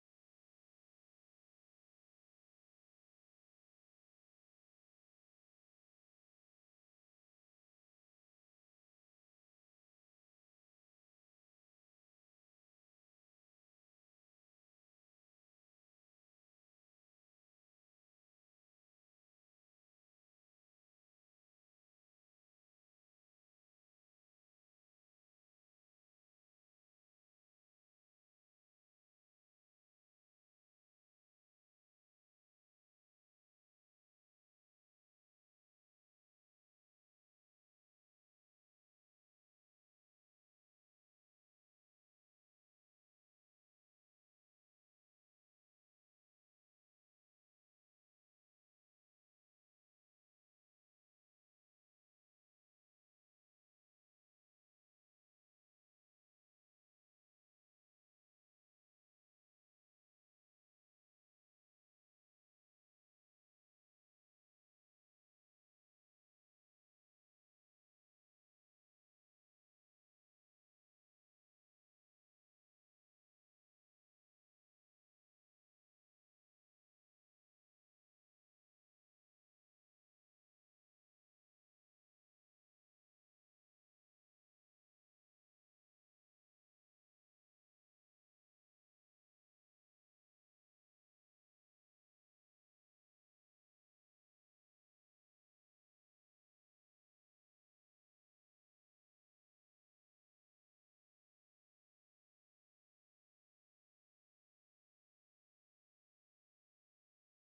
海外マニアによるノイズレスのレストア音源を初収録！！
※試聴用に実際より音質を落としています。